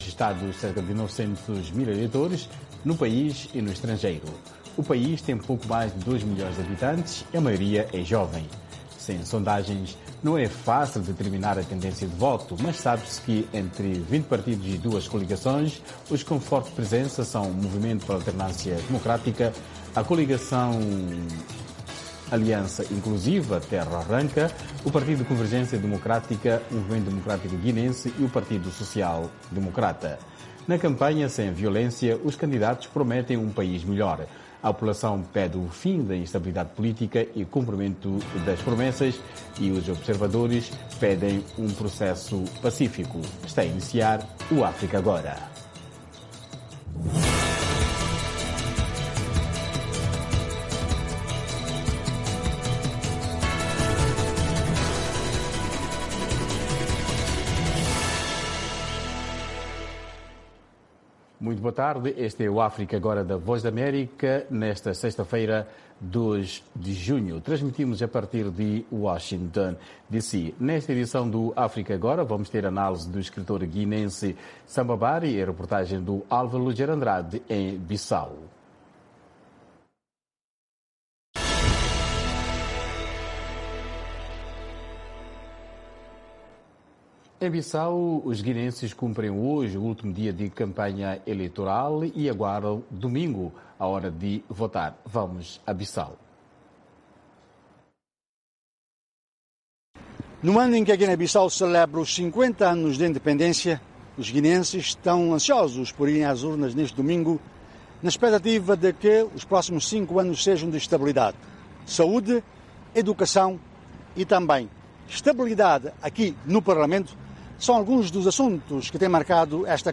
Um debate sobre temas actuais da África Lusófona.